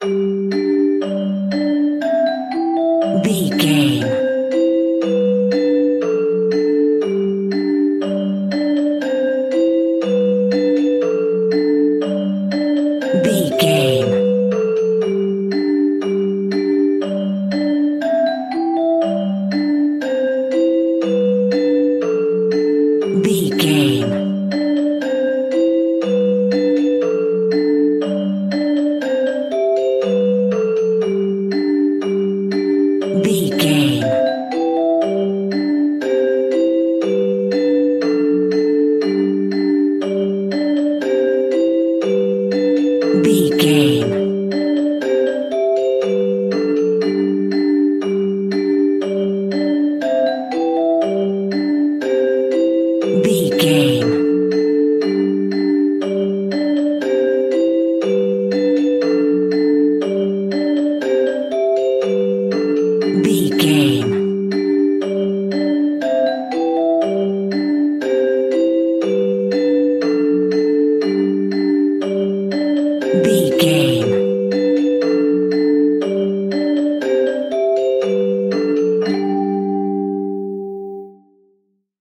Ionian/Major
nursery rhymes
childrens music